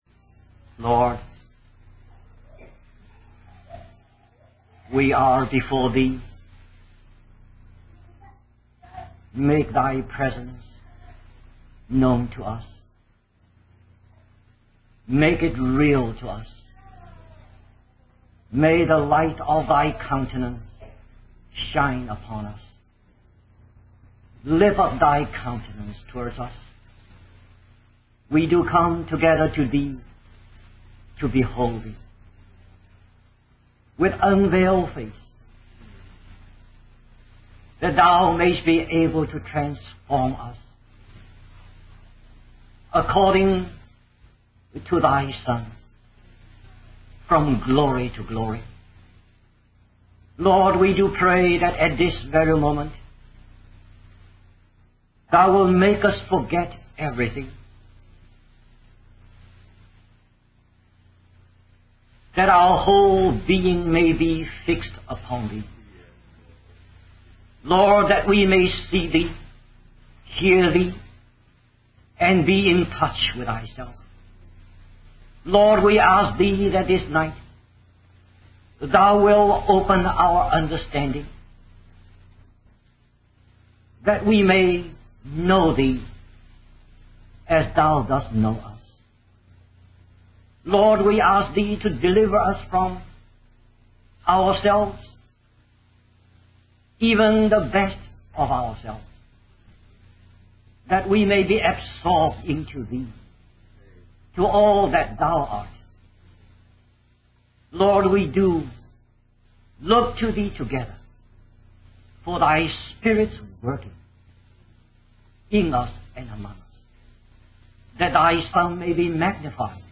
In this sermon, the speaker reflects on the importance of using our entire body, not just our words, to effectively communicate. He emphasizes that the body is the vessel through which the riches of Christ can be expressed and manifested to the world.